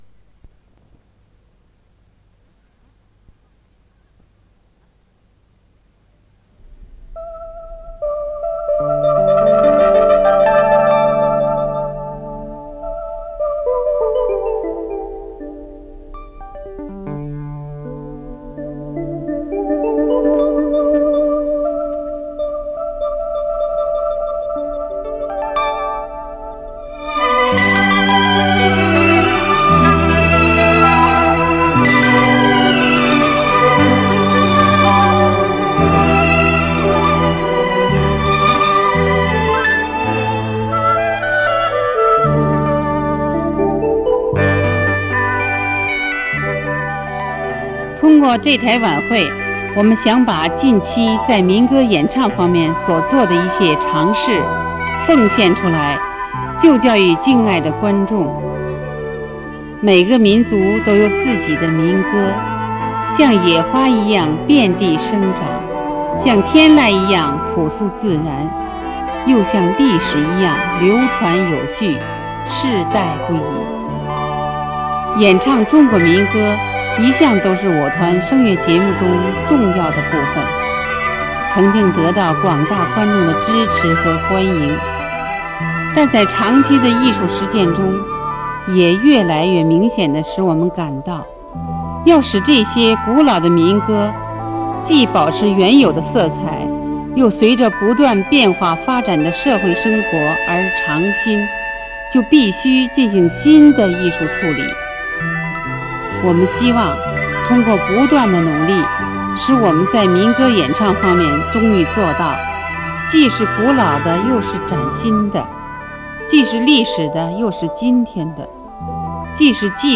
歌曲演唱会